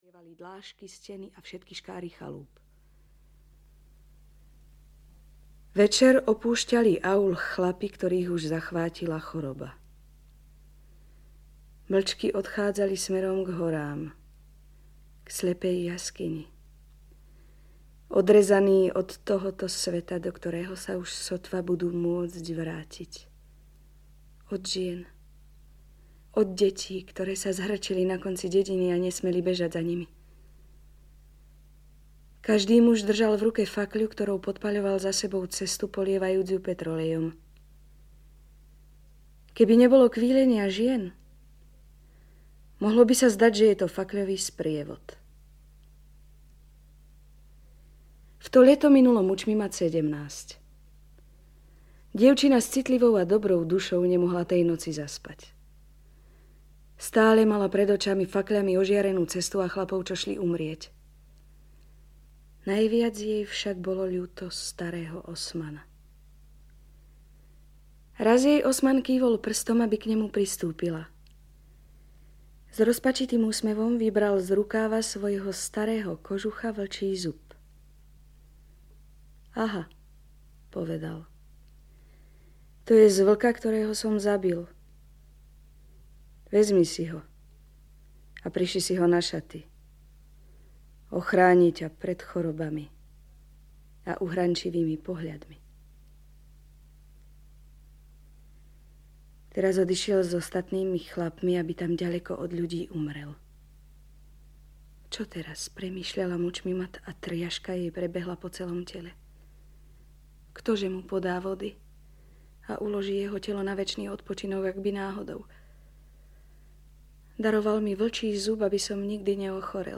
Celostátní přehlídka uměleckého přednesu Neumannovy Poděbrady vycházela od roku 1974 na deskách Supraphonu.
Ukázka z knihy